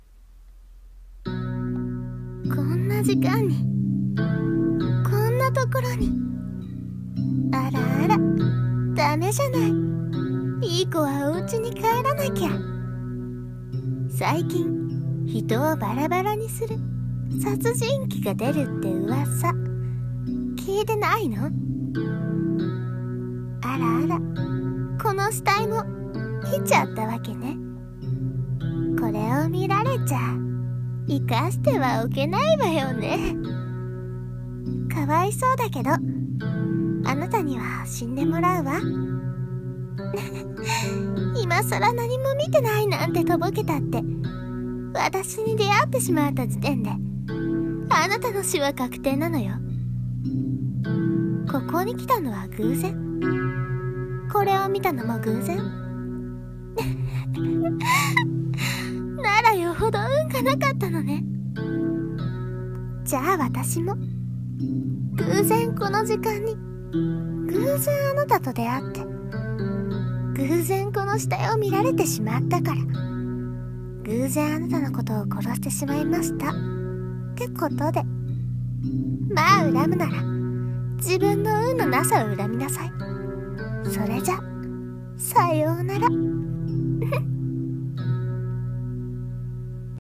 【一人声劇】
【悪役】